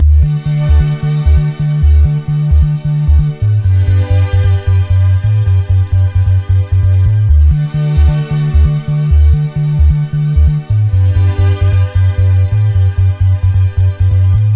Rap_Divinerhythm.mp3